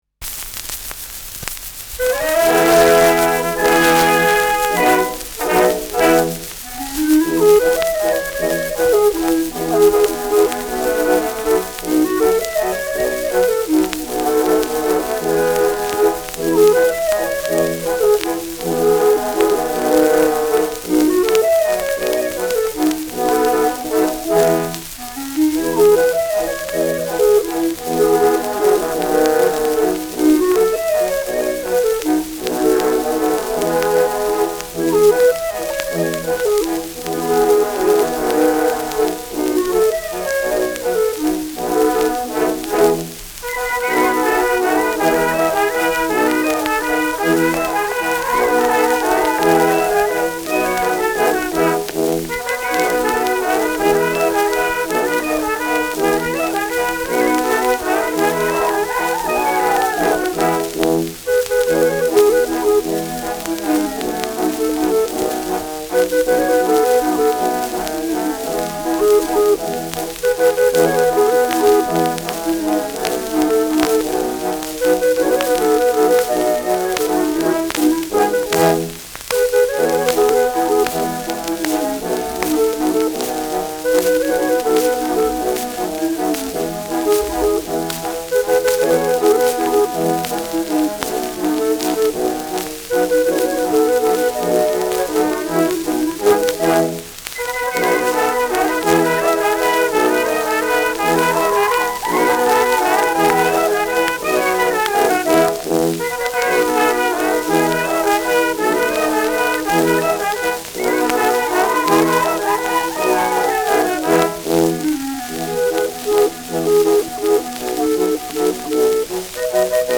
Schellackplatte
präsentes Rauschen : präsentes Knistern : abgespielt : leiert : gelegentliches Knacken : gelegentliches Nadelgeräusch
Mit Juchzer.